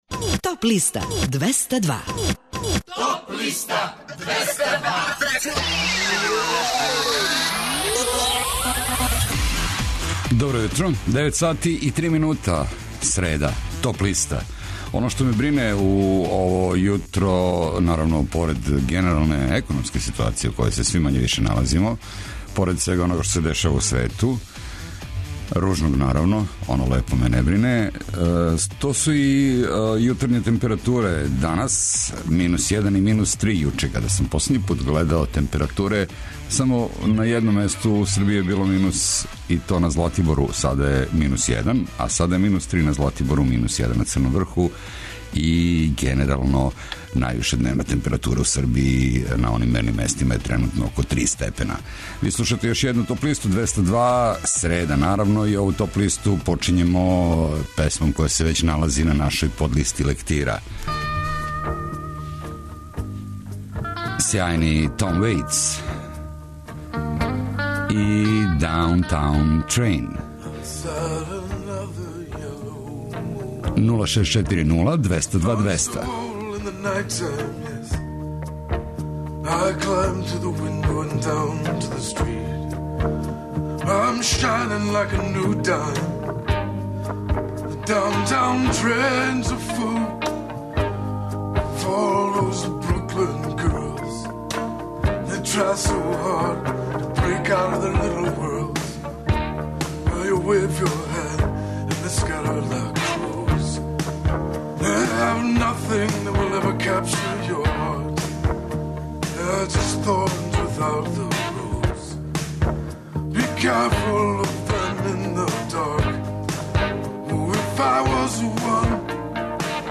преузми : 24.67 MB Топ листа Autor: Београд 202 Емисија садржи више различитих жанровских подлиста.